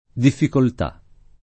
difficoltà [ diffikolt #+ ] s. f.